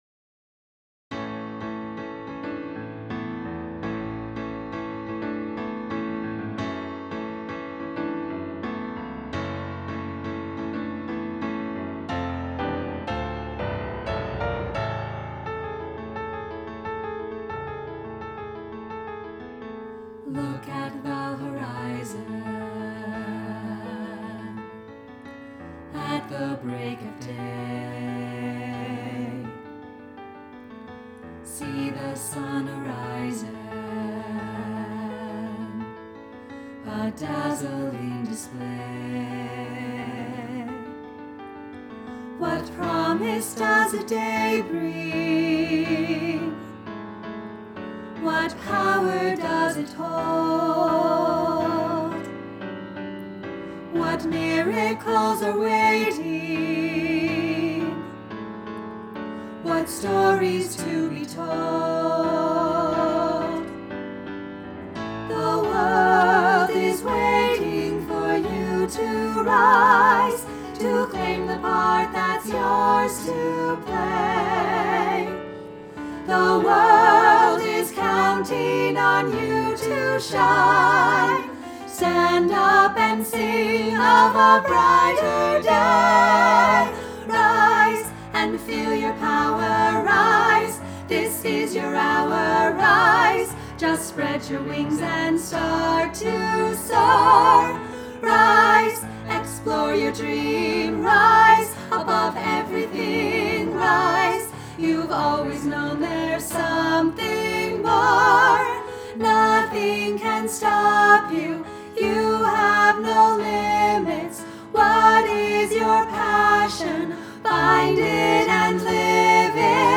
Rise! All Parts (Melody, Harmony 1, Harmony 2) Recording